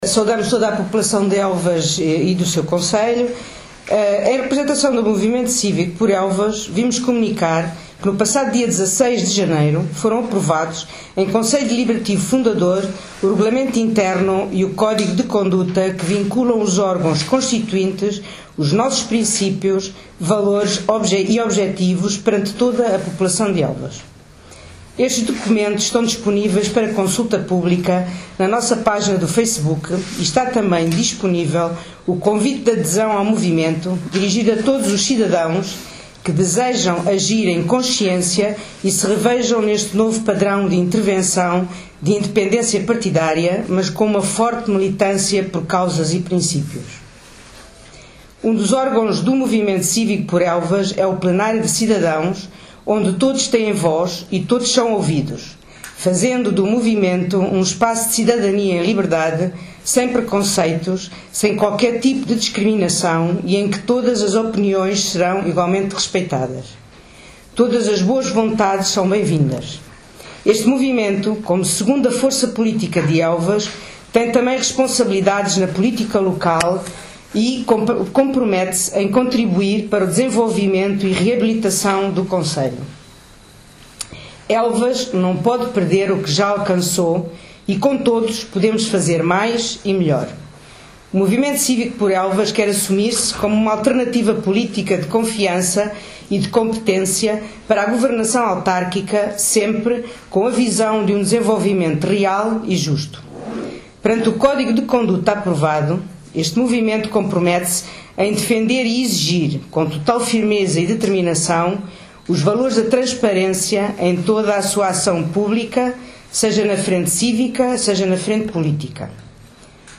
O Movimento Cívico Por Elvas, realizou conferência de imprensa, esta terça-feira dia 23 pelas 13 horas, no centro da Juventude.